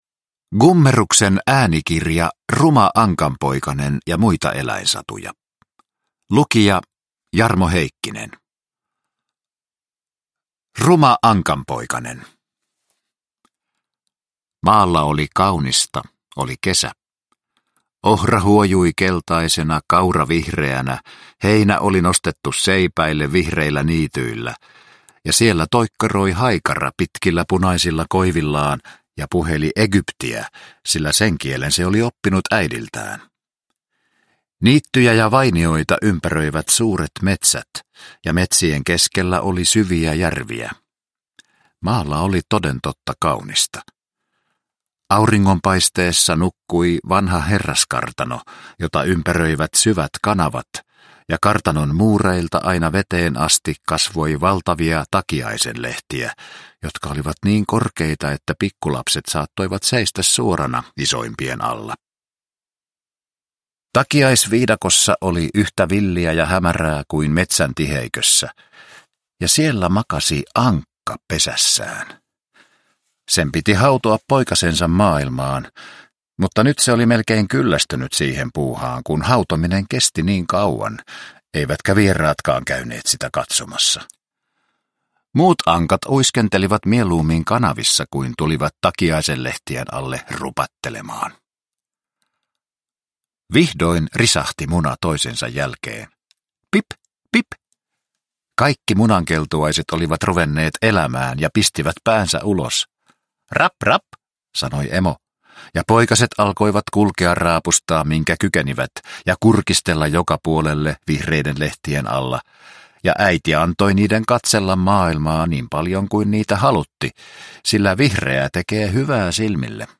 Ruma ankanpoikanen ja muita eläinsatuja – Ljudbok – Laddas ner